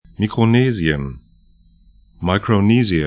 Pronunciation
Mikronesien mikro-'ne:sĭən Micronesia maɪkroʊ'ni:ziə en